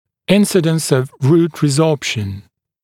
[‘ɪnsɪdəns əv ruːt rɪˈzɔːpʃn] [-ˈsɔːp-][‘инсидэнс ов ру:т риˈзо:пшн] [-ˈсо:п-]случаи появления резорбции корней, частота появления резорбции корней